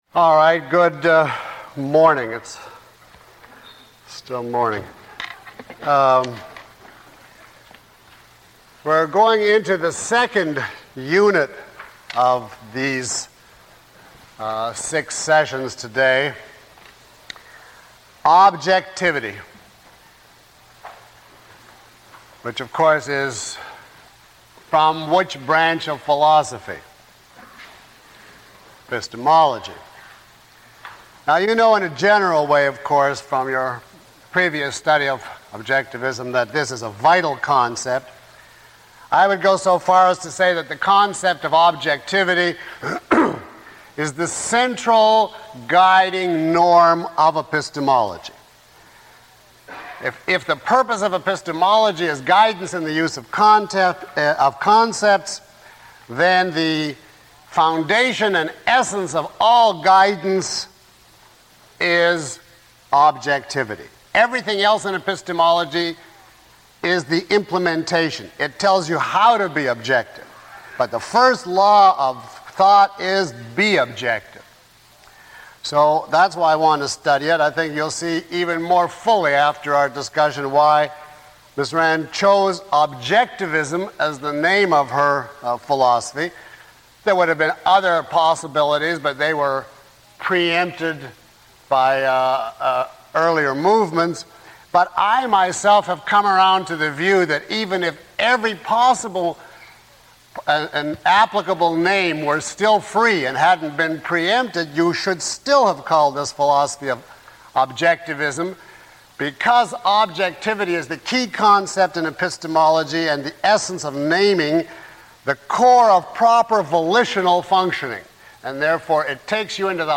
Lecture 03 - Objectivism - The State of the Art.mp3